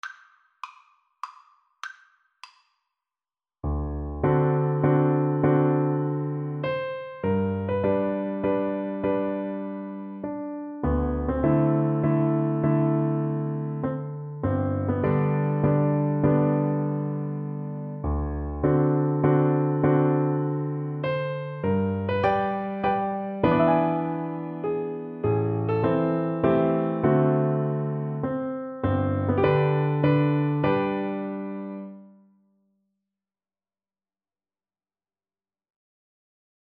3/4 (View more 3/4 Music)
Classical (View more Classical Recorder Music)